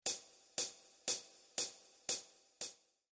Mikrofonierung der Hi-Hat
Ein Abstand von ca. zehn Zentimeter über der Spieloberfläche und eine Neigung von ca. 45 Grad mit Ausrichtung auf diesen Anschlagbereich sind eine gute Ausgangsbasis für eine verzerrungsfreie Übertragung des Hi-Hat-Klangs.
Der Klang in der Nähe der Kuppe wird insgesamt dünner und heller sein, zum Rand hin wird der Klang voluminöser und etwas dunkler werden.
HiHat Kuppe